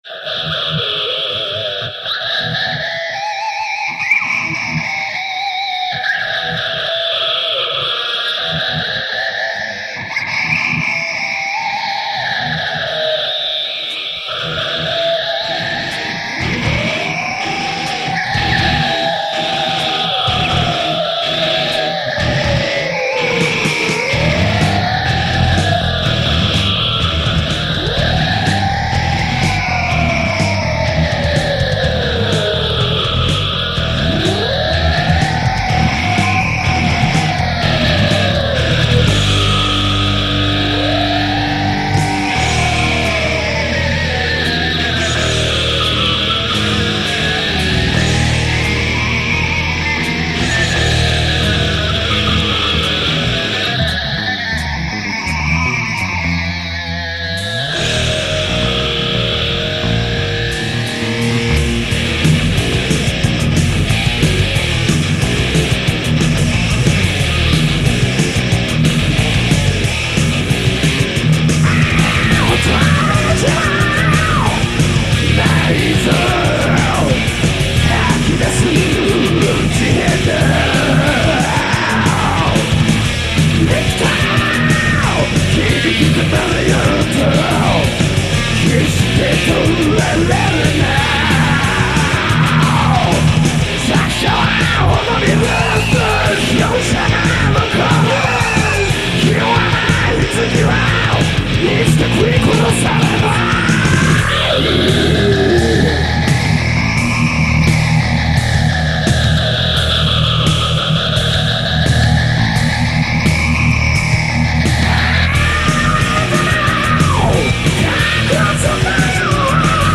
HARD ROCK系
＊ボリューム注意
ドラムはチープな（笑）リズムマシーン打ち込み。4トラによる宅録、DEMO TAPEより。